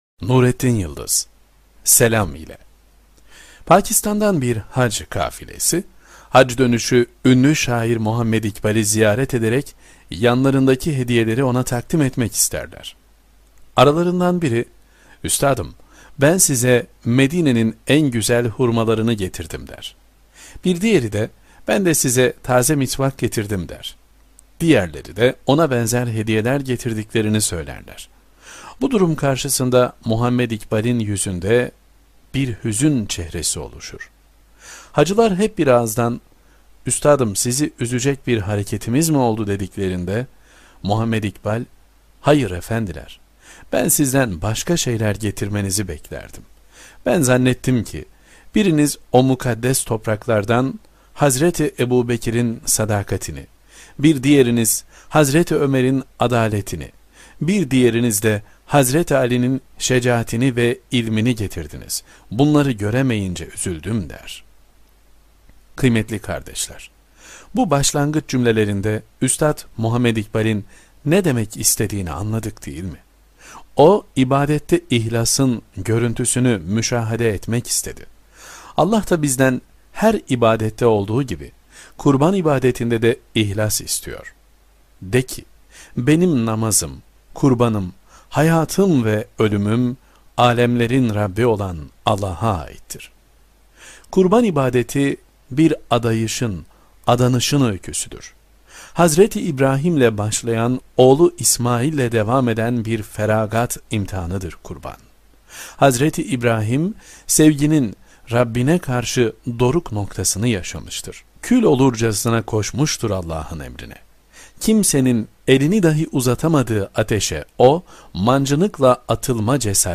2. Sesli Makale